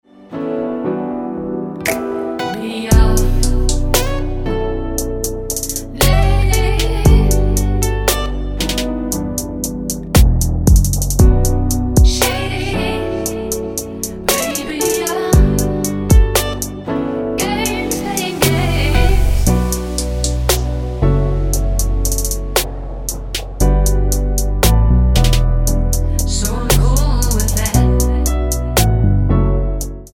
--> MP3 Demo abspielen...
Tonart:Bm mit Chor